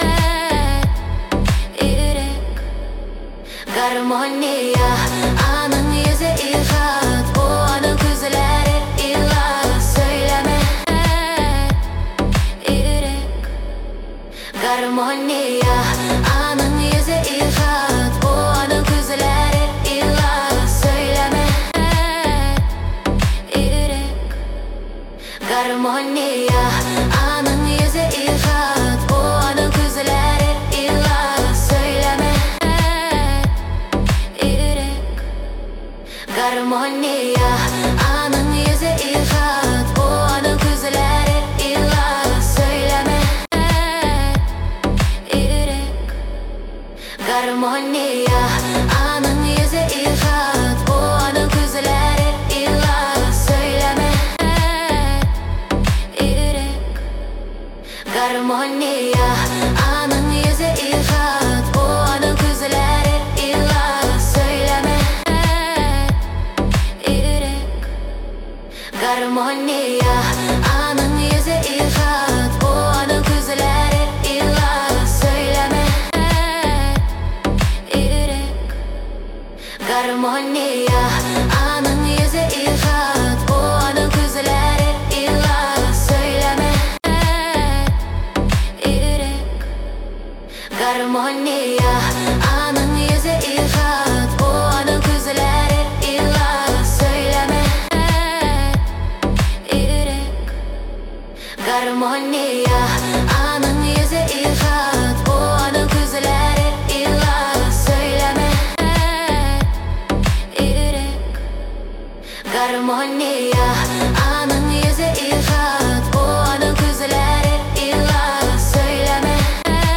Поп музыка, Новинки